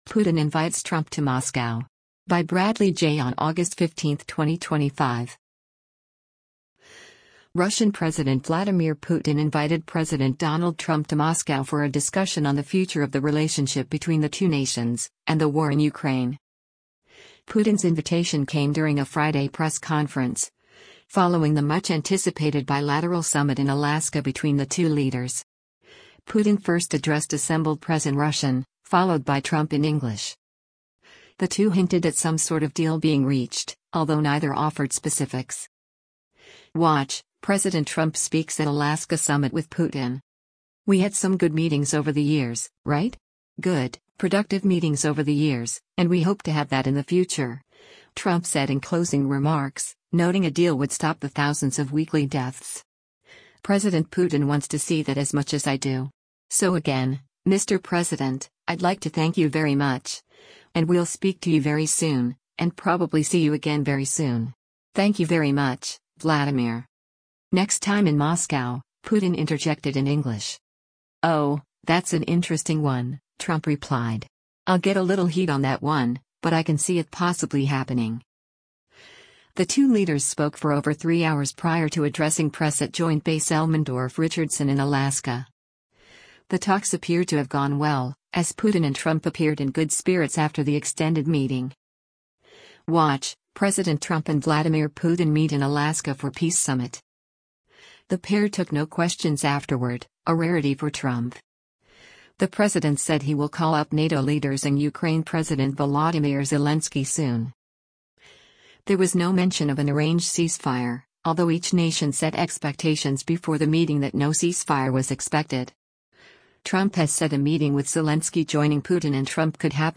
Putin’s invitation came during a Friday press conference, following the much-anticipated bilateral summit in Alaska between the two leaders. Putin first addressed assembled press in Russian, followed by Trump in English.